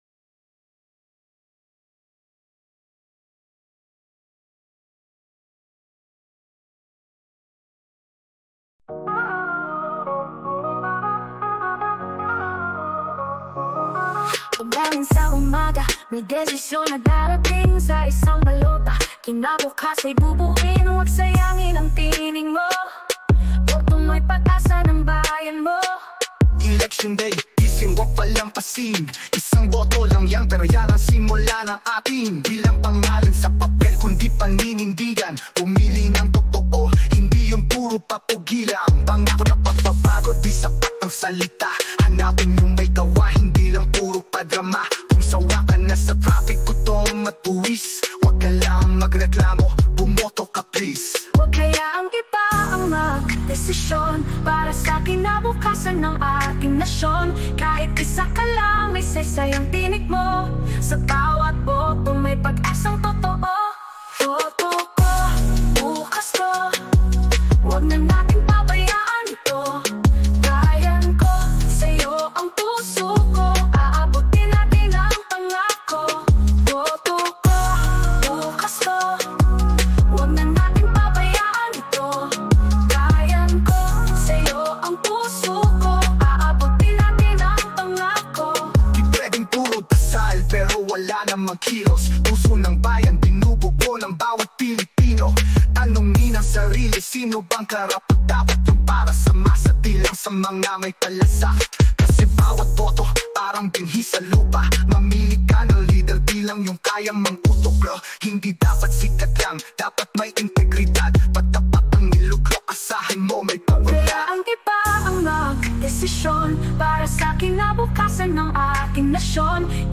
Tagalog R&B/Hip-hop anthem